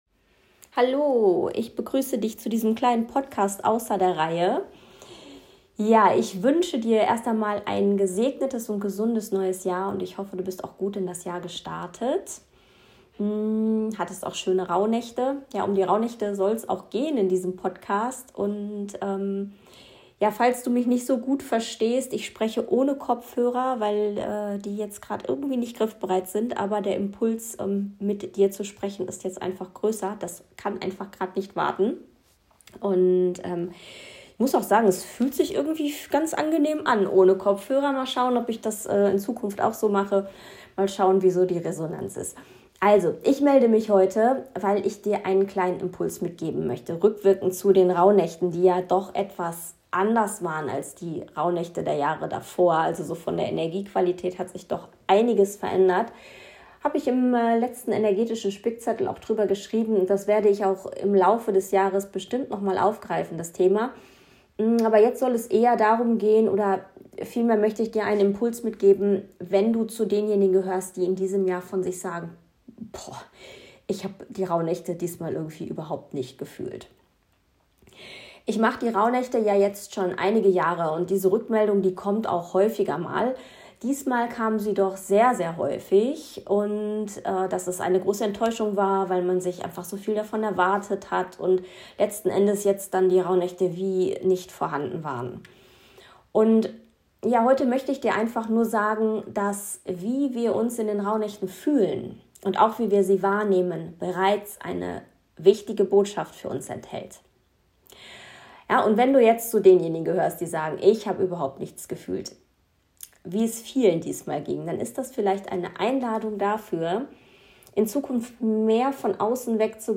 Gelesene Blog-Beiträge